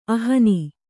♪ ahani